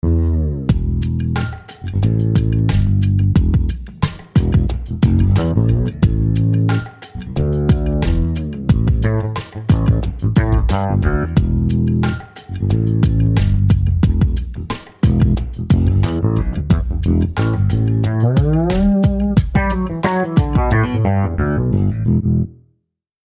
Fretless_unusual03.mp3